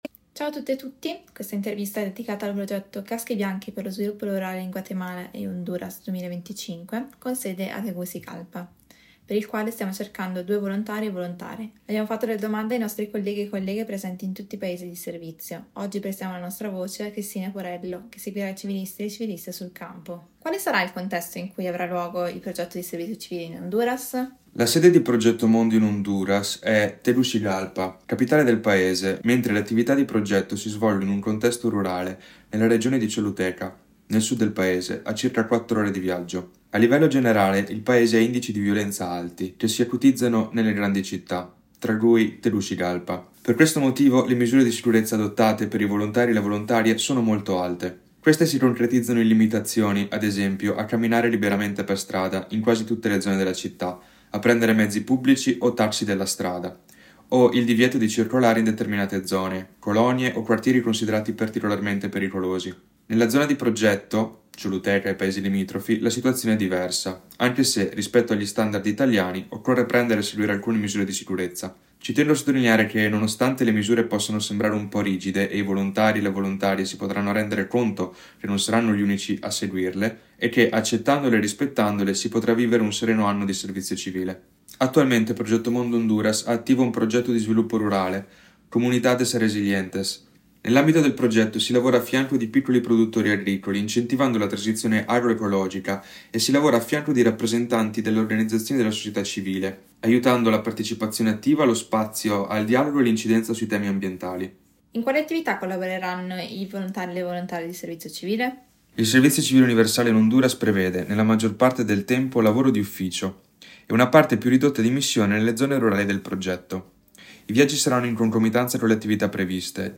INTERVISTA 2